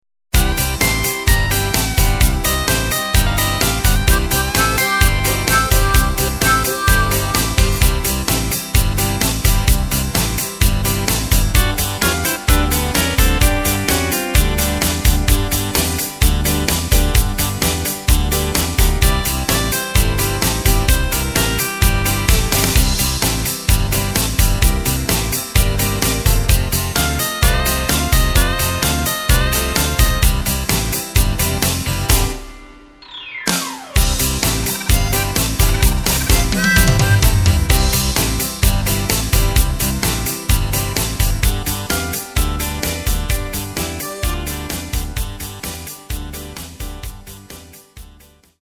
Takt: 4/4 Tempo: 128.50 Tonart: C
Country-Beat